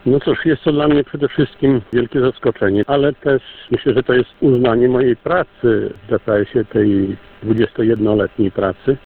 Nie krył radości.